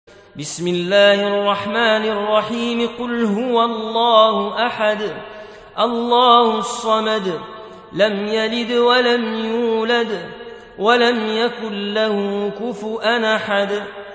Surah Sequence تتابع السورة Download Surah حمّل السورة Reciting Murattalah Audio for 112. Surah Al-Ikhl�s or At-Tauh�d سورة الإخلاص N.B *Surah Includes Al-Basmalah Reciters Sequents تتابع التلاوات Reciters Repeats تكرار التلاوات